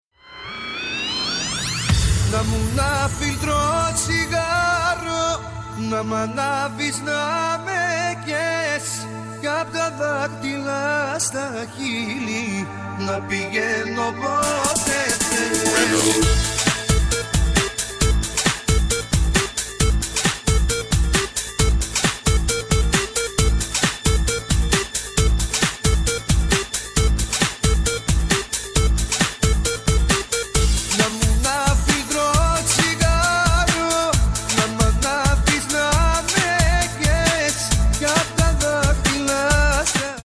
• Качество: 128, Stereo
мужской вокал
dance
house